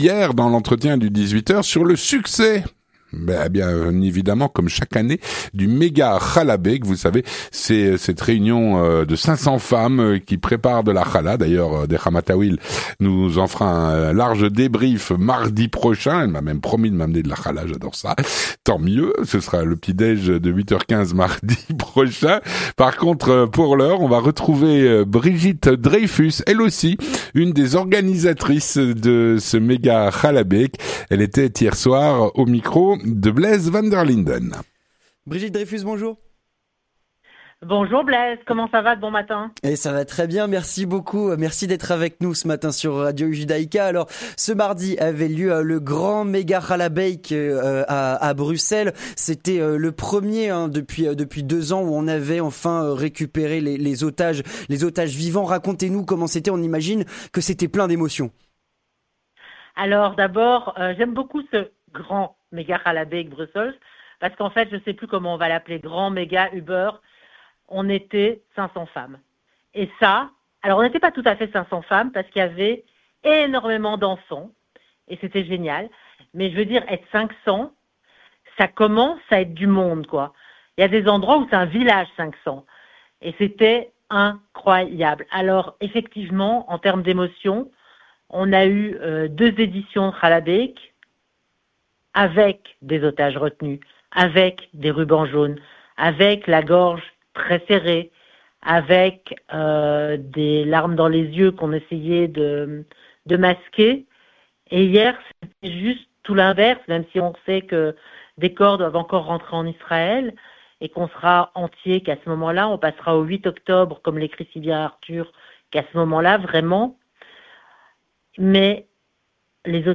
L'entretien du 18H - Retour sur le Mega Challah Bake.